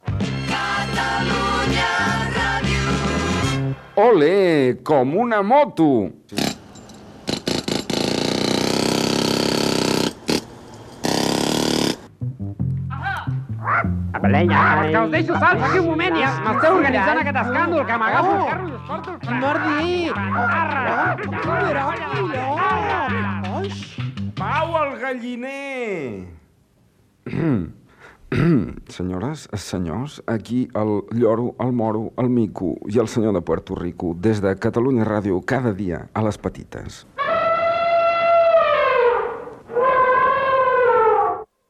Indicatiu emissora i promoció del programa
FM